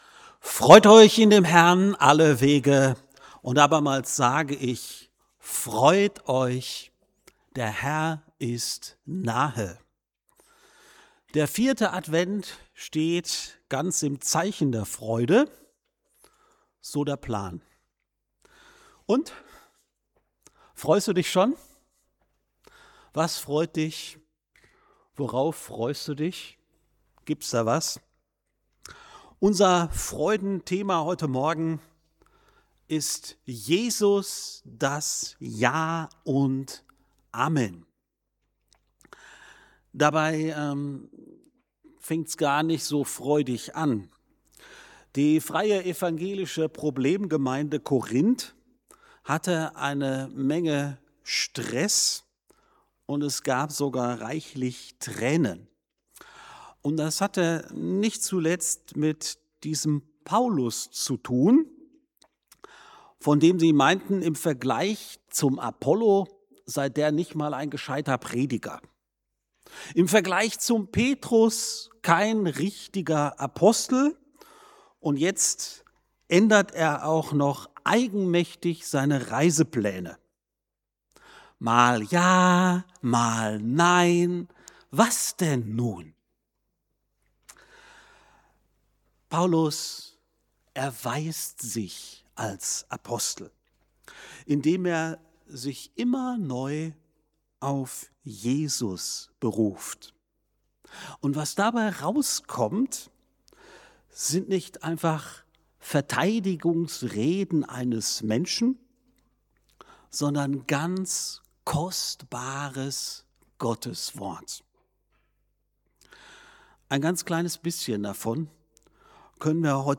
~ FeG Aschaffenburg - Predigt Podcast